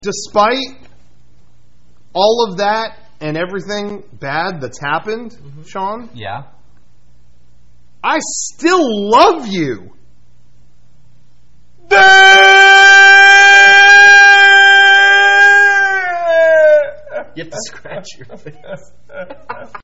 Play Mega64 Best Cry Ever - SoundBoardGuy
mega64-best-cry-ever.mp3